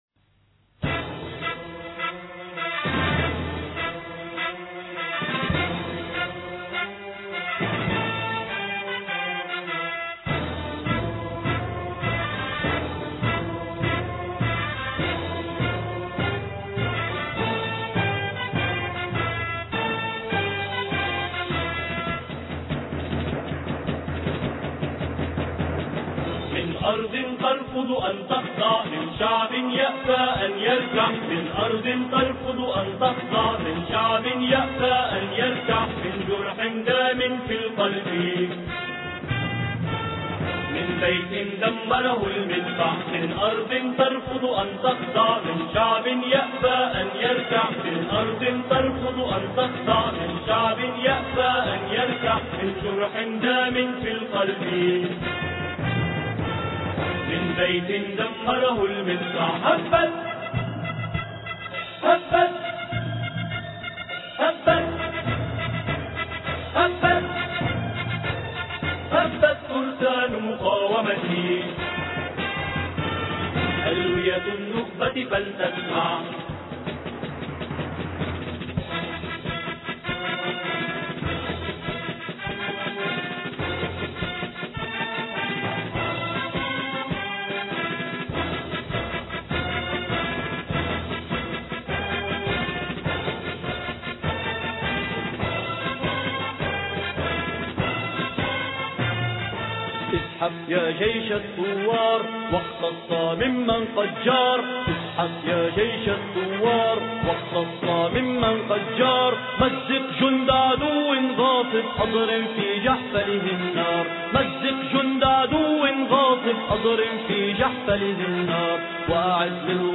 اذهب يا جيش الثوار الإثنين 23 يونيو 2008 - 00:00 بتوقيت طهران تنزيل الحماسية شاركوا هذا الخبر مع أصدقائكم ذات صلة الاقصى شد الرحلة أيها السائل عني من أنا..